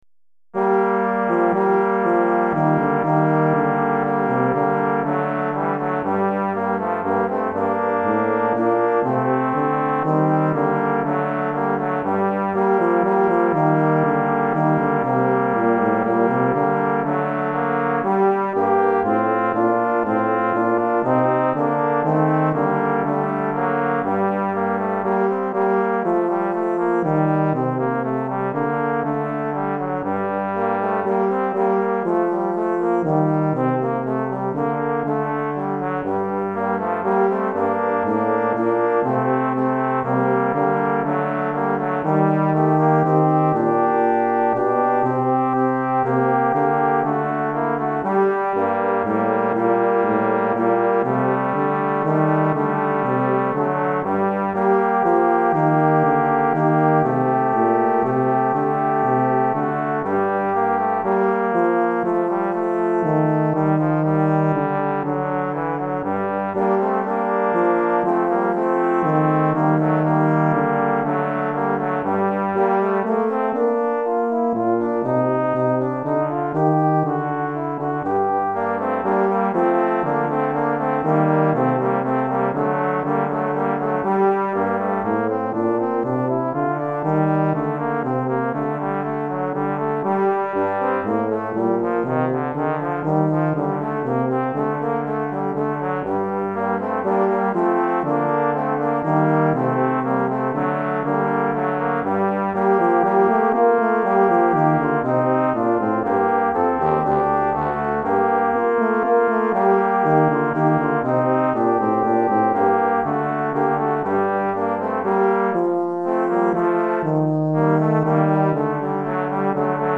Répertoire pour Trombone - 2 Trombones et Continuo